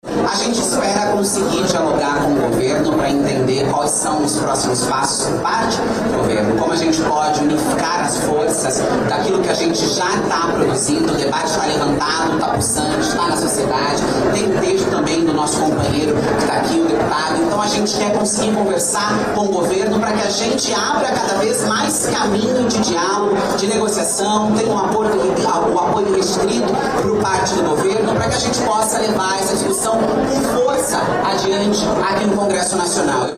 Em coletiva de imprensa na noite desta quarta-feira, a parlamentar disse que tem dialogado com ministros do governo Lula.